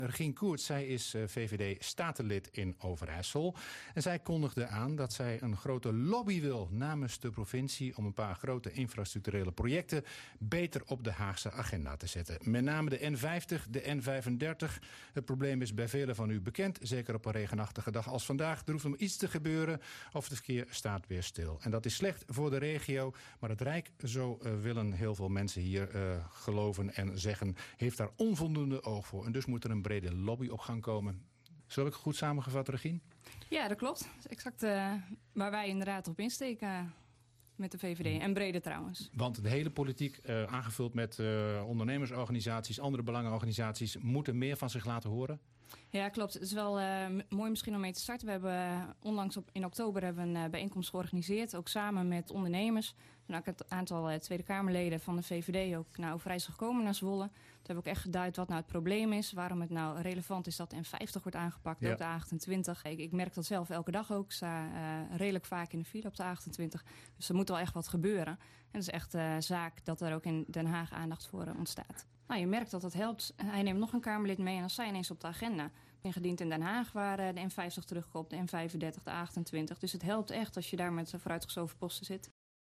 VVD-Statenlid Regien Courtz was gisteren te gast bij RTV Oost. Ze sprak er over wat moet gebeuren met de Overijsselse infrastructuur.